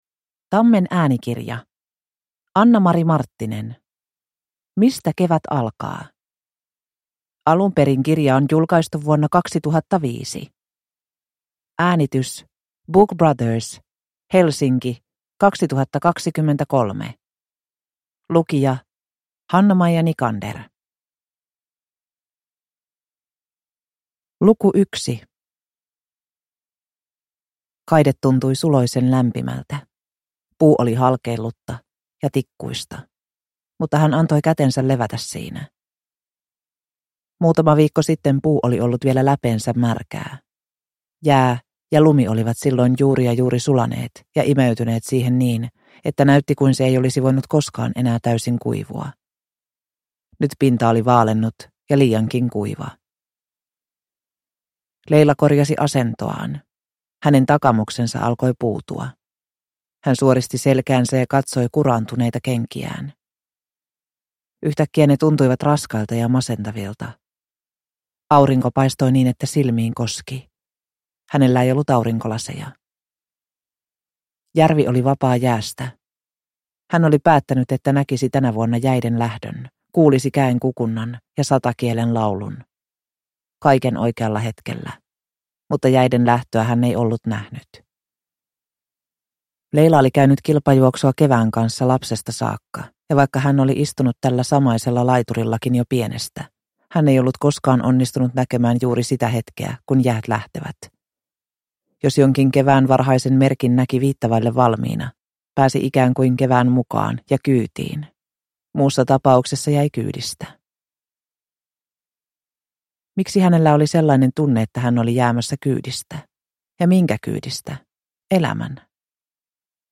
Mistä kevät alkaa (ljudbok) av Annamari Marttinen